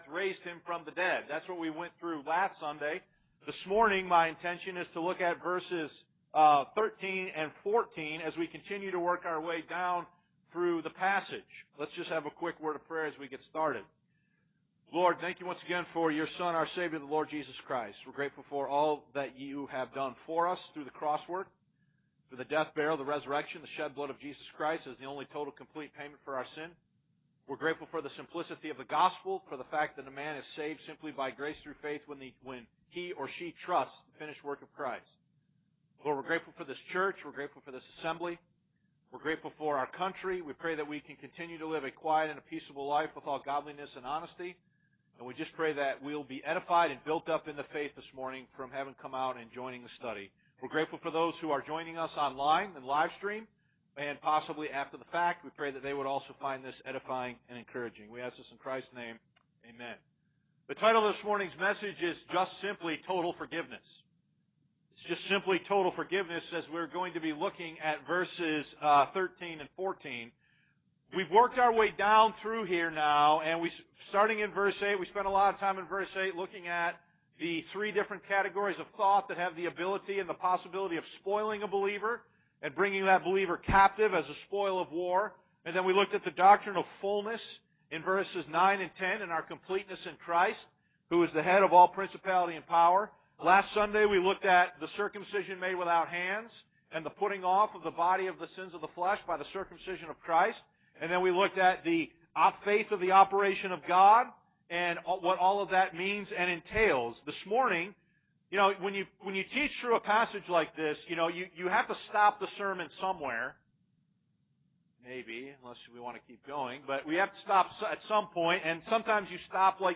Please note that the preaching starts @ the 13:20 mark in the video.